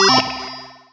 player_kicked.wav